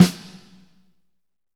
Index of /90_sSampleCDs/Northstar - Drumscapes Roland/DRM_Pop_Country/SNR_P_C Snares x